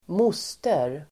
Ladda ner uttalet
Uttal: [²m'os:ter]